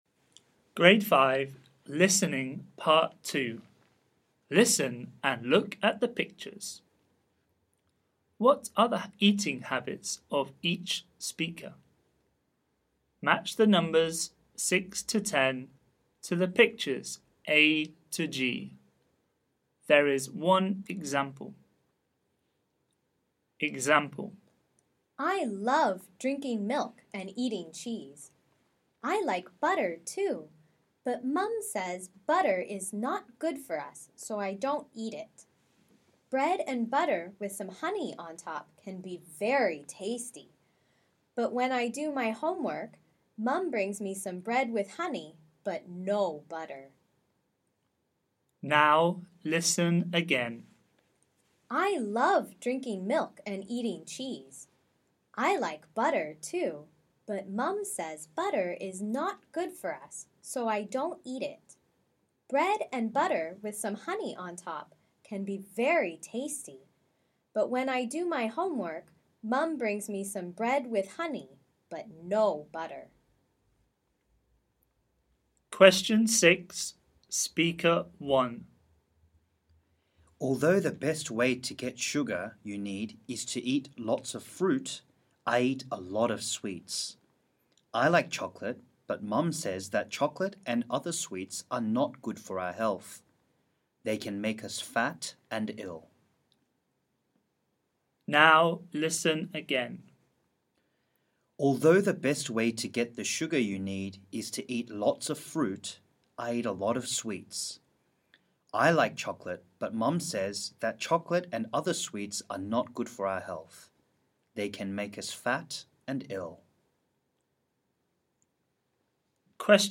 اسم الملف: نموذج امتحان الاستماع للصف الخامس mp3 ل...